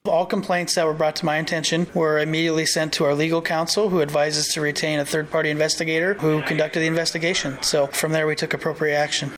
Humboldt City Administrator Cole Bockelmann, who was the receiving end of allegations of the city turning a blind eye says the City did conduct an investigation into the allegations.